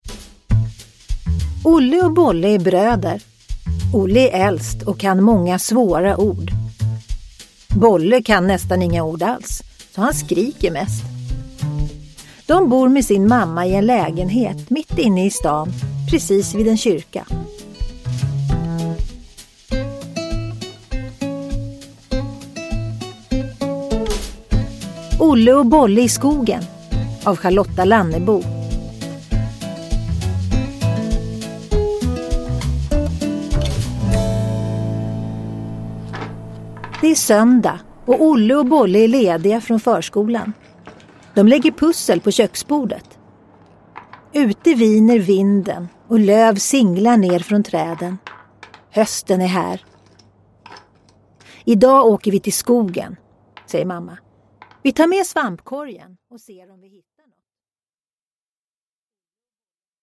Olle och Bolle i skogen – Ljudbok – Laddas ner
Uppläsare: Tova Magnusson